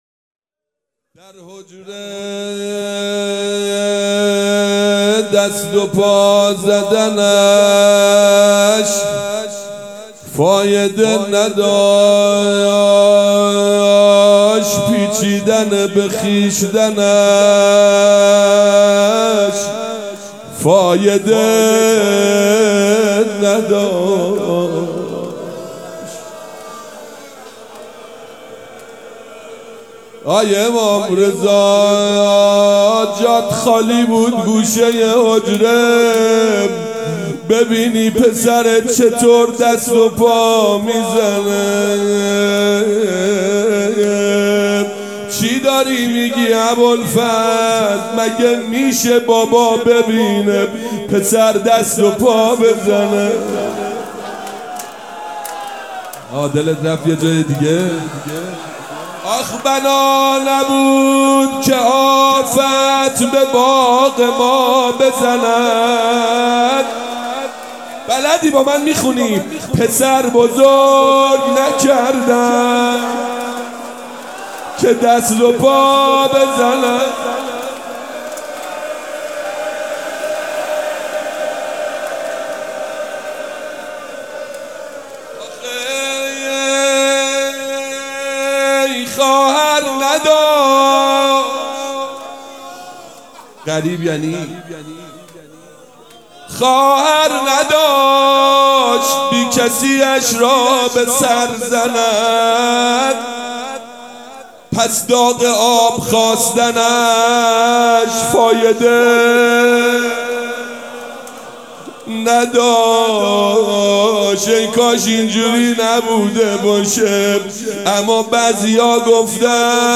هیئت ریحانه الحسین سلام الله علیها
روضه
مداح